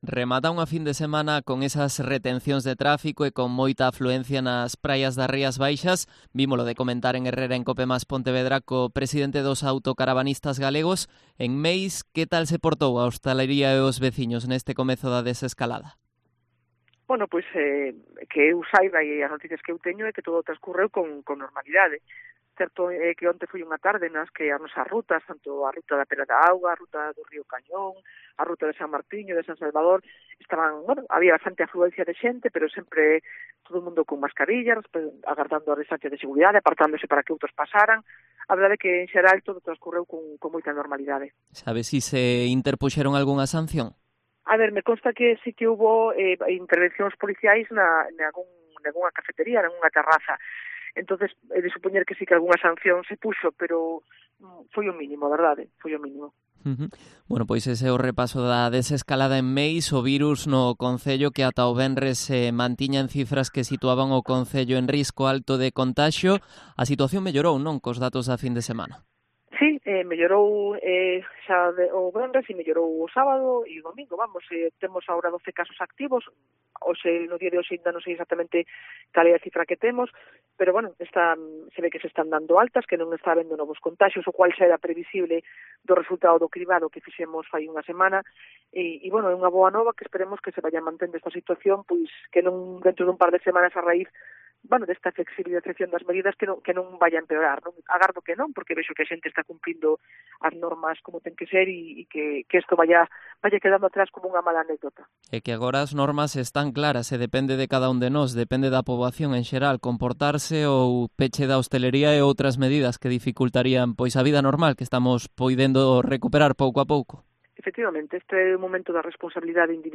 Entrevista a Marta Giráldez, alcaldesa de Meis